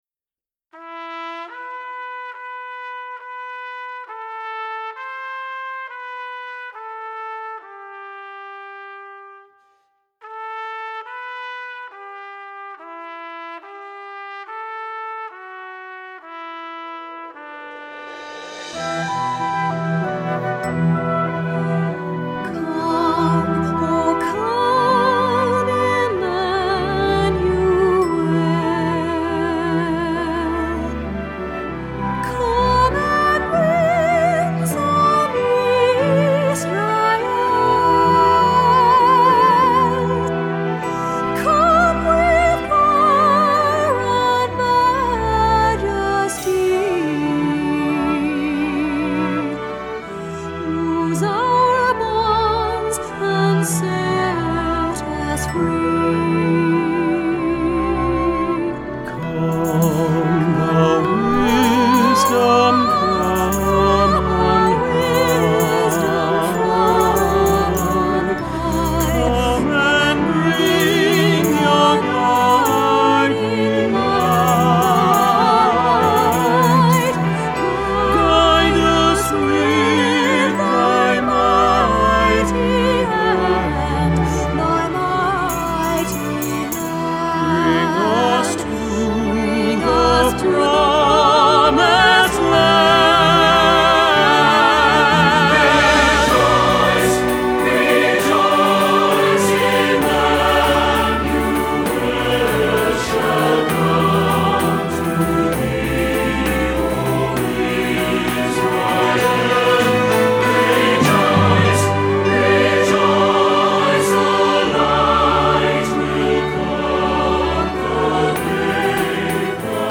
Voicing: S/a/t/b - Rehearsal